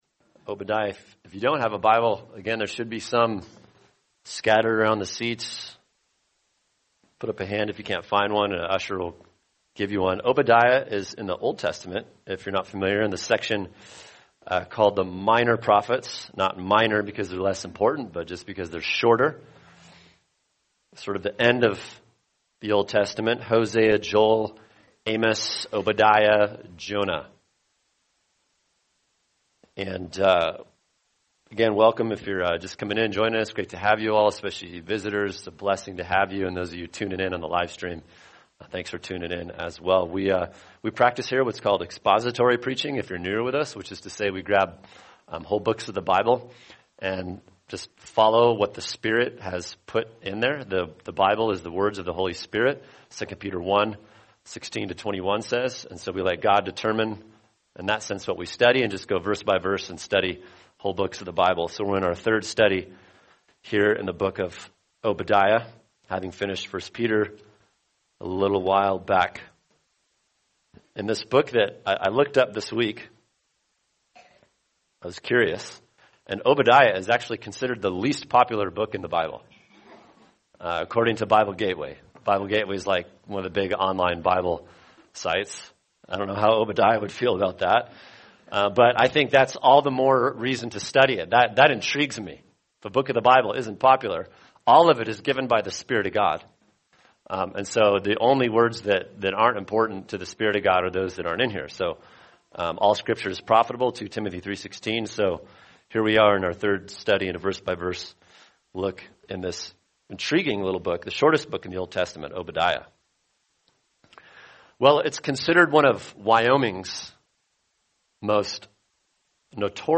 [sermon] Obadiah 1:10-16 God’s Care for His Afflicted People | Cornerstone Church - Jackson Hole